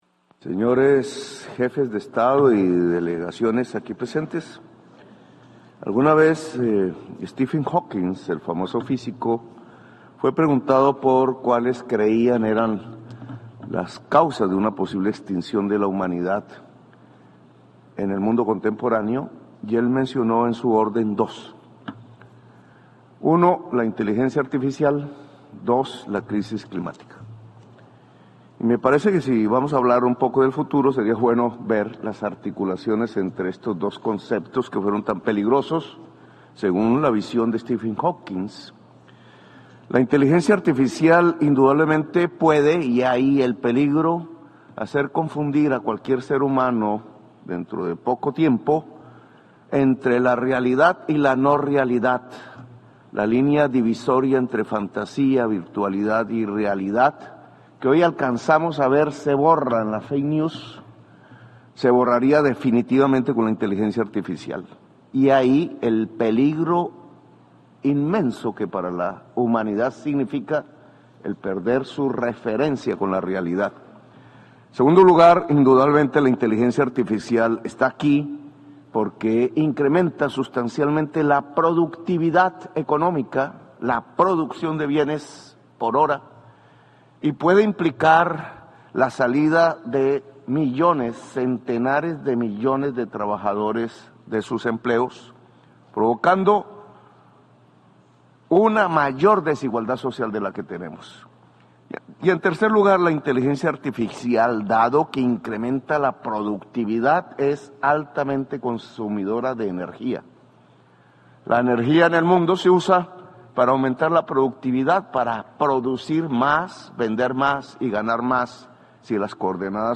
Presidente Gustavo Petro en el Foro del sector privado de la Cumbre del Futuro
Naciones Unidas, 23 de septiembre de 2024